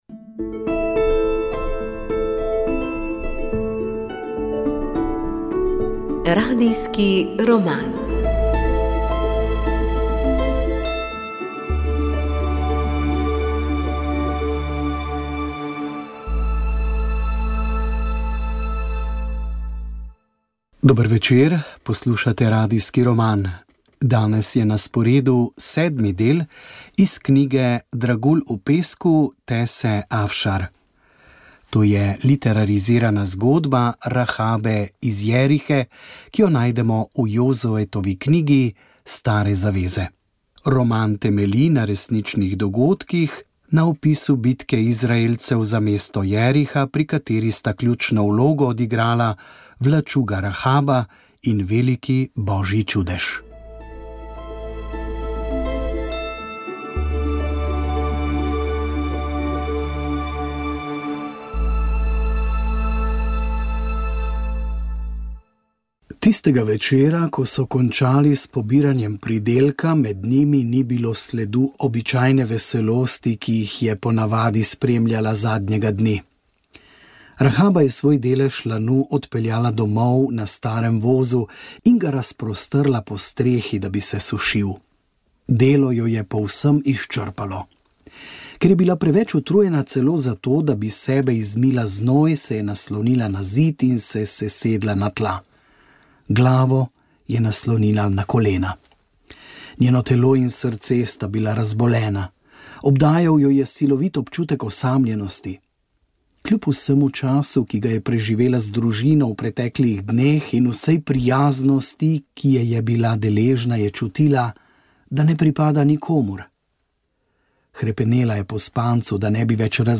Radijski roman